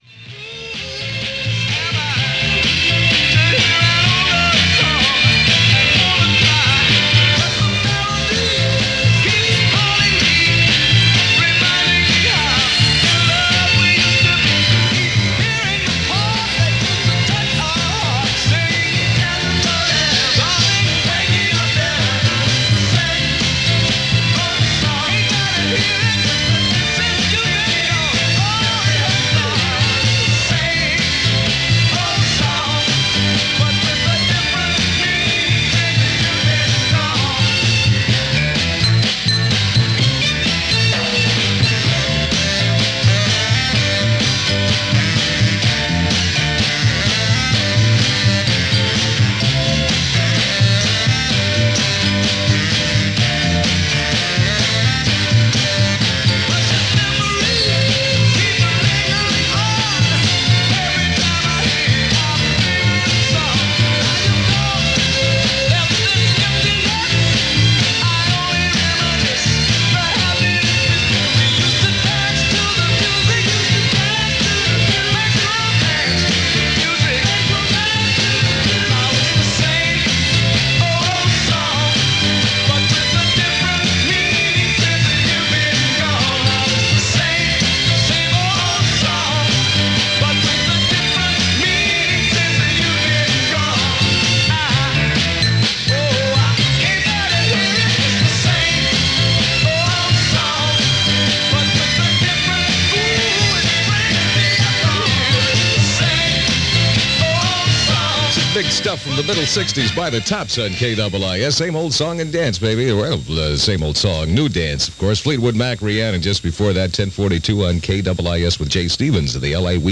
But right now, right this second, it’s getting through the week and the next and the next and the next – lucky for you there are tunes and a radio and you have friends and you’re all in this together.